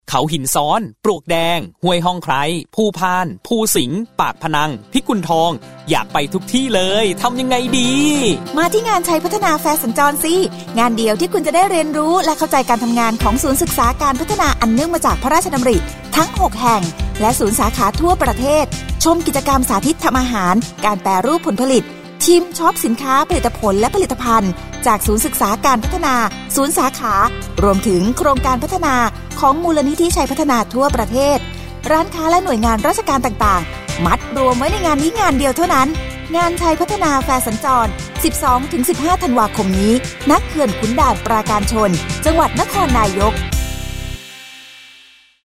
ปี 2567 : สปอตประชาสัมพันธ์ ตอนที่ 5 งานชัยพัฒนาแฟร์สัญจร